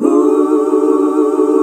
HUH SET D.wav